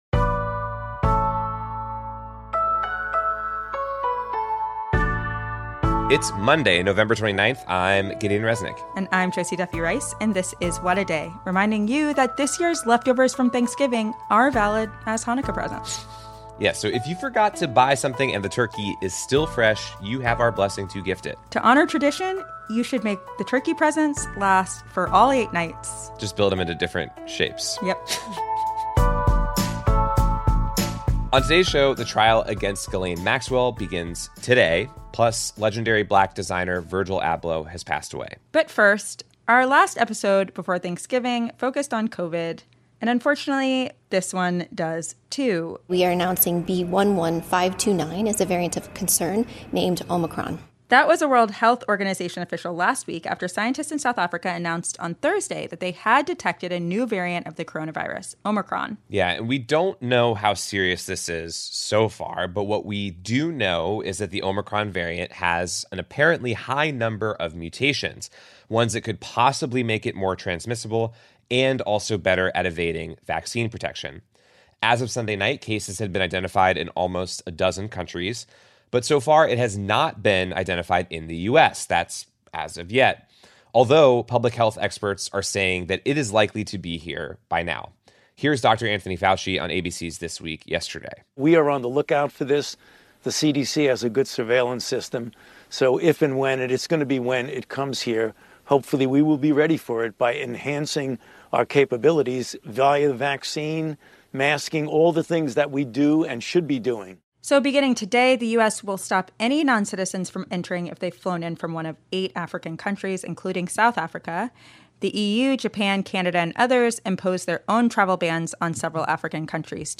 Scientists in South Africa announced on Thursday that they detected a new variant of the coronavirus: Omicron. Cases caused by the variant have been identified in almost a dozen countries, but not yet in the U.S. Epidemiologist and host of “America Dissected” Dr. Abdul El-Sayed gives us a better sense of what we know and the much bigger amount of things we don’t.